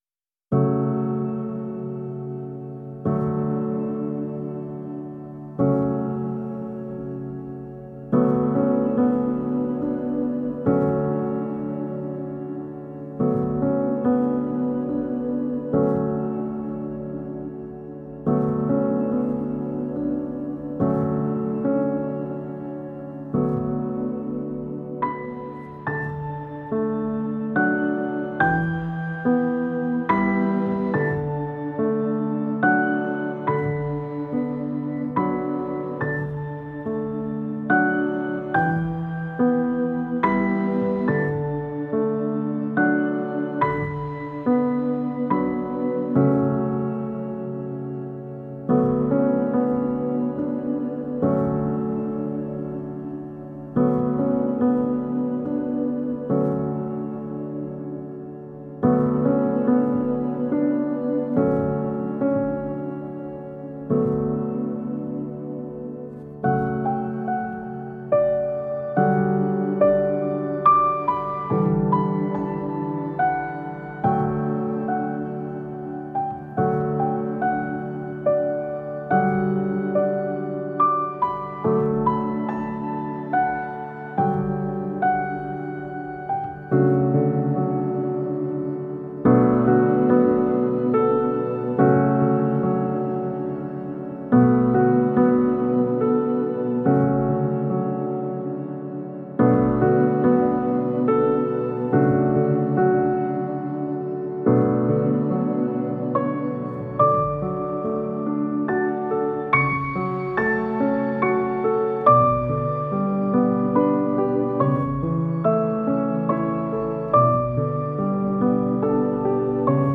Genre: filmscore, easylistening.